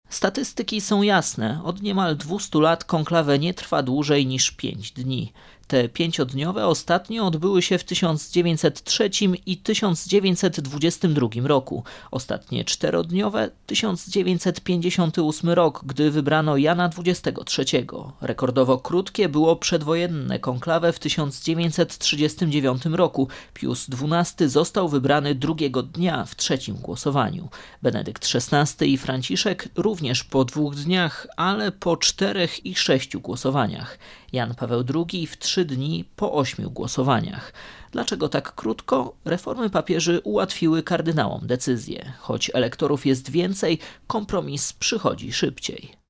AUDIO: Relacje dotyczące konklawe.